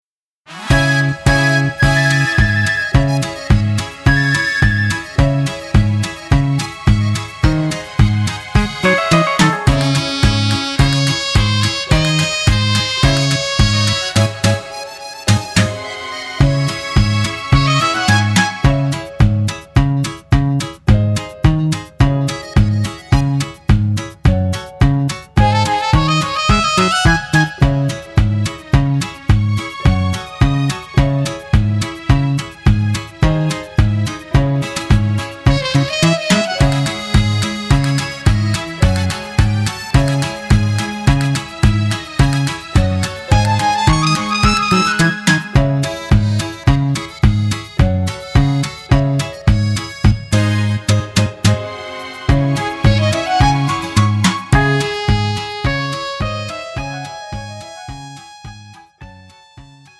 음정 여자키 2:55
장르 가요 구분 Pro MR